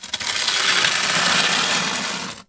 scrape.wav